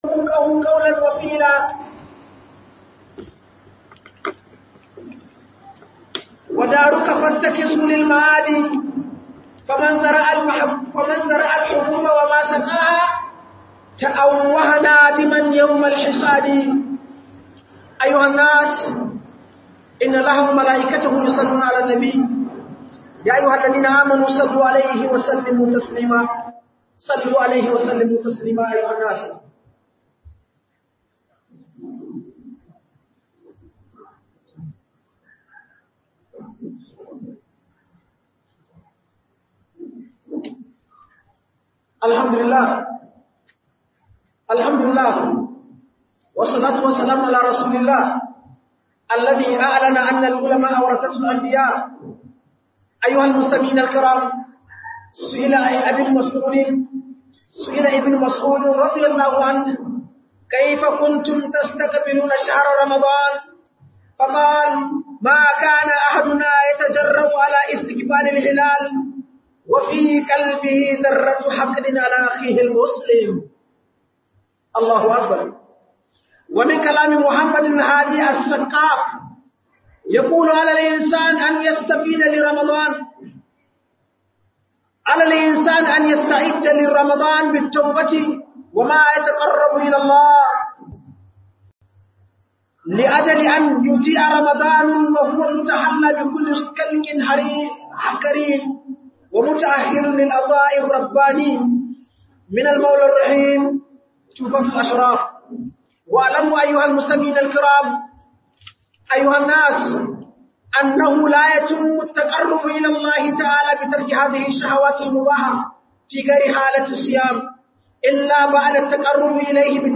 Tanadi Don Fiskantar Ramadan - HUDUBA